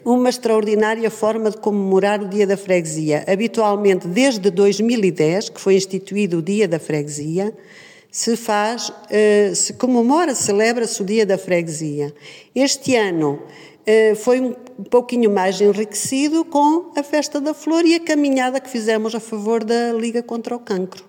O evento foi organizado pela Junta de Freguesia, e a sua presidente, Adelaide Carvalho, salientou que este dia foi marcado pela diferença: